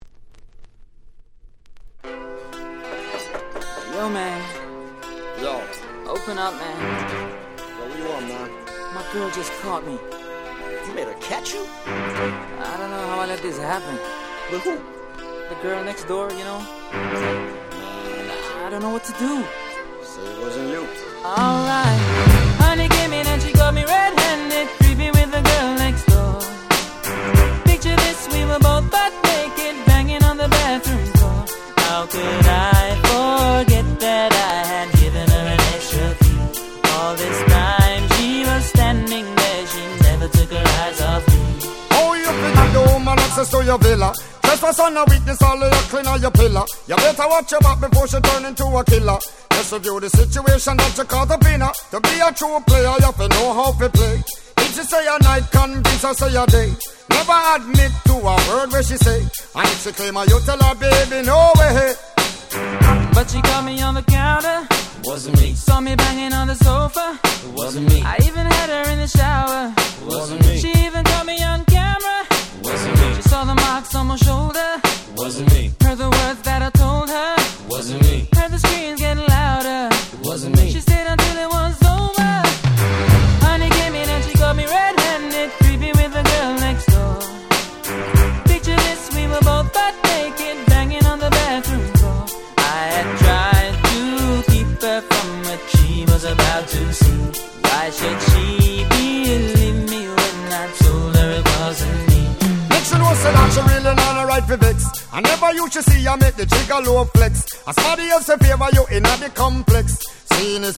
01' Super Hit Reggae !!